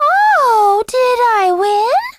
Peach's victory voice clip in Super Smash Bros. Melee